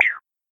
sfx_interact.wav